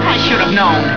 Here you will find tons of great sounds and other downloads from actual episodes of Scooby Doo. Choose from any of over 150 wav files, each of excellent sound quality.